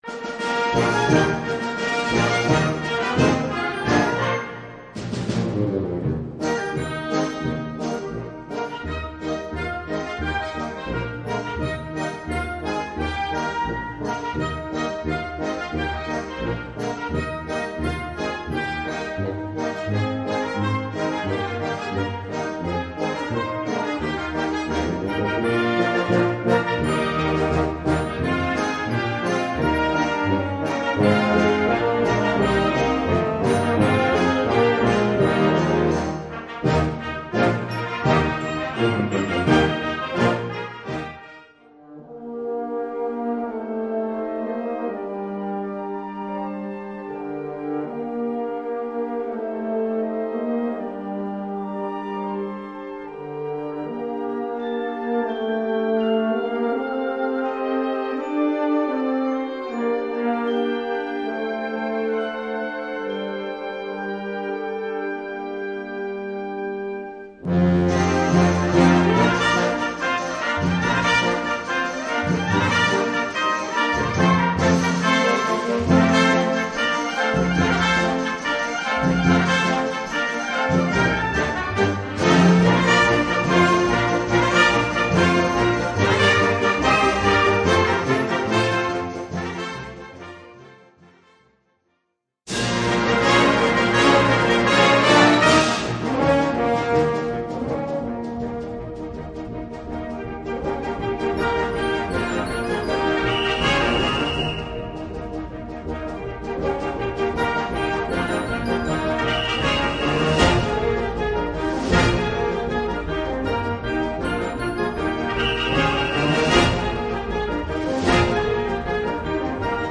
Gattung: Suite
Besetzung: Blasorchester
Suite aus israelischen Volkslieder.